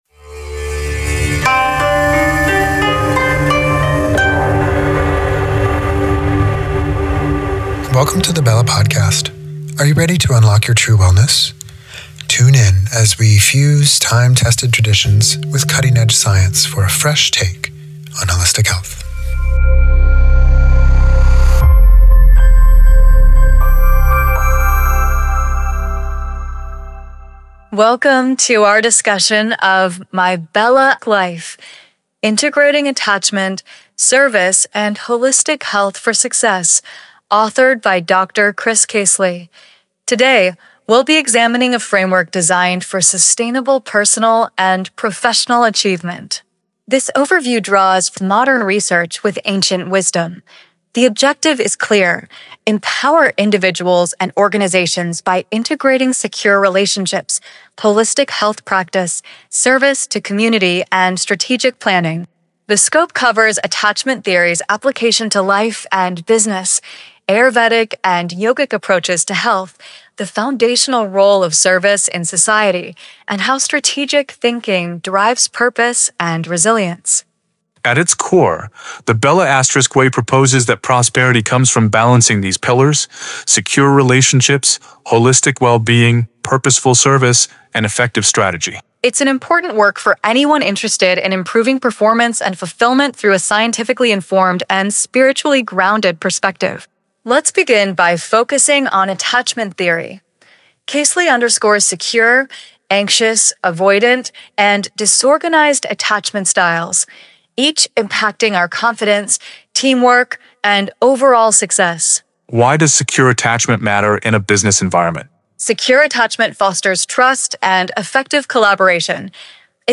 Listen to the Synopsis